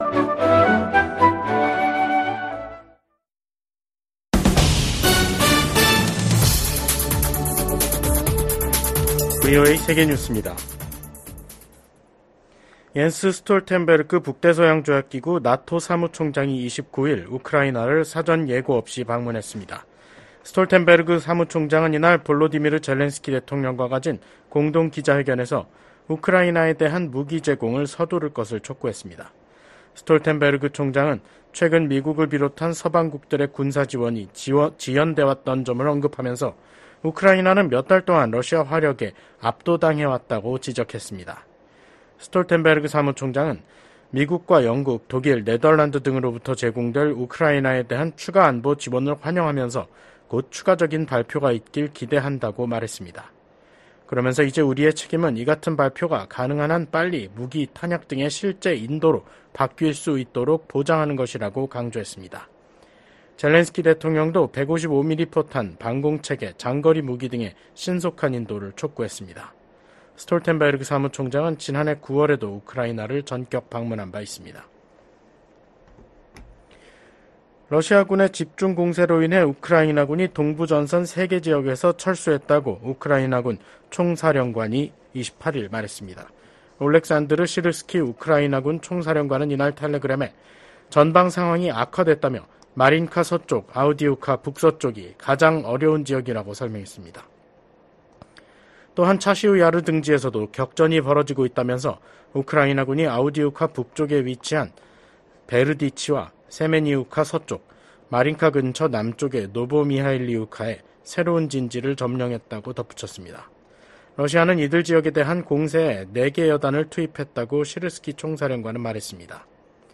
VOA 한국어 간판 뉴스 프로그램 '뉴스 투데이', 2024년 4월 29일 3부 방송입니다. 미국과 한국, 일본이 제14차 안보회의를 열고 지속적인 3국간 안보협력 의지를 재확인했습니다. 유엔 주재 미국 부대사는 중국과 러시아의 반대로 북한의 핵 프로그램에 대한 조사가 제대로 이뤄지지 못했다고 지적했습니다. 북한이 김정은 국무위원장이 참관한 가운데 신형 240mm 방사포탄 검수사격을 실시했습니다.